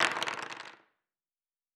Dice Multiple 7.wav